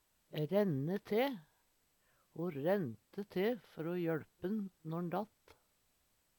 DIALEKTORD PÅ NORMERT NORSK renne te forte seg utan å tenkje fyrst Eksempel på bruk Ho rennte te før o jøLpe`n når`n datt.